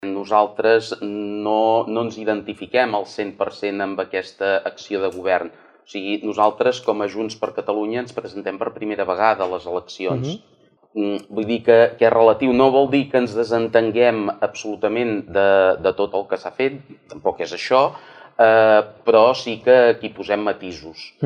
Debat Electoral Palafrugell 2019